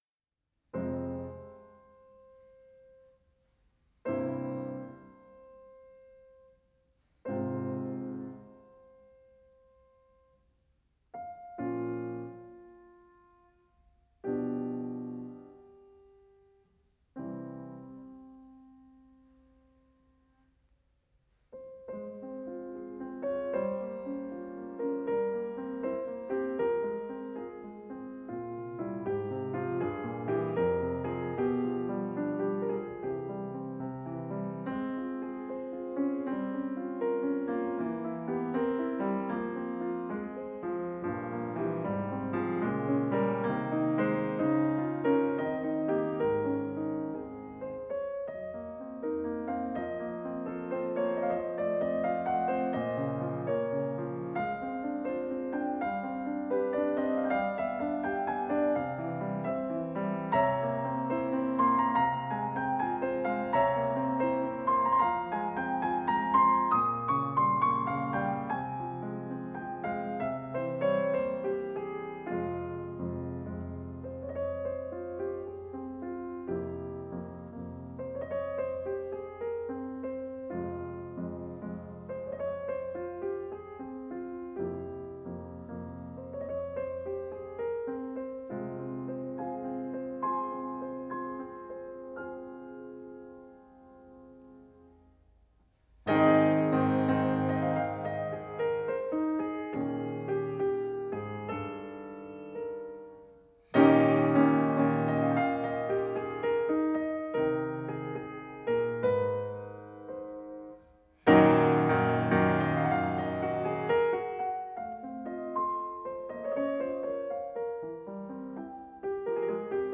Ноктюрн. Фа минор